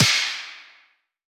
tap3.wav